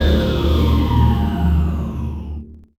ht-locomotive-break.ogg